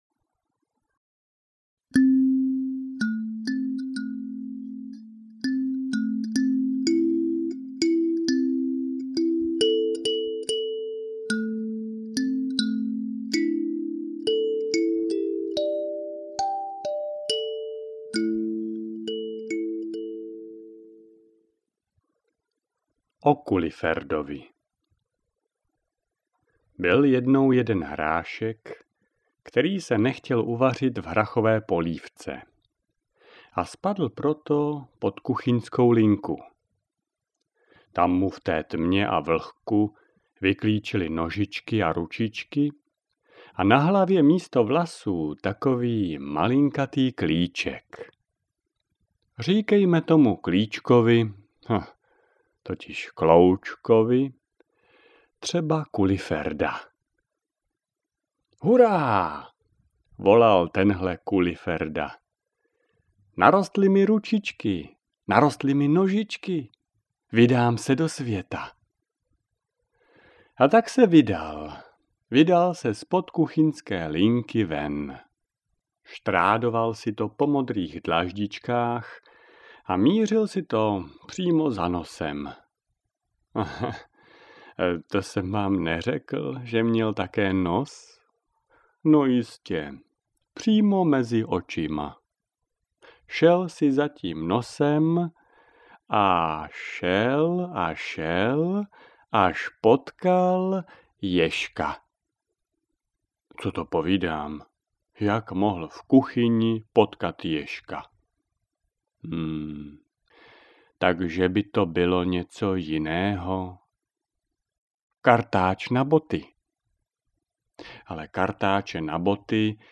O Kuliferdovi audiokniha
Ukázka z knihy